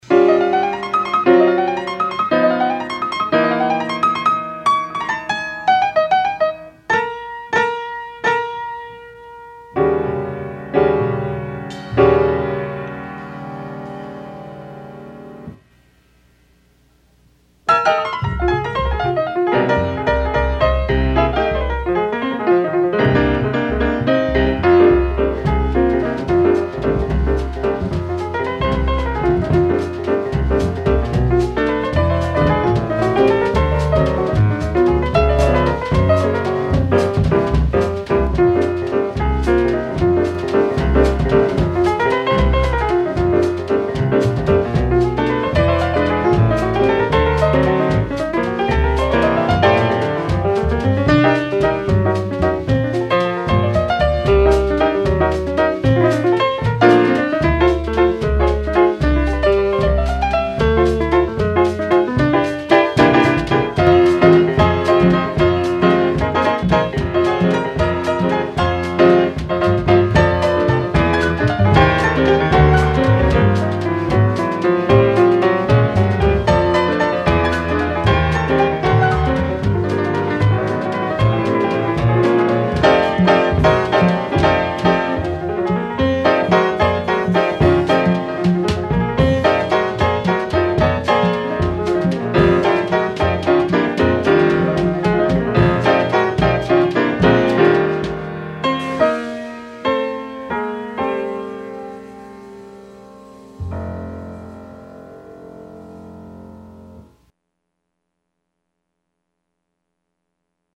(piano)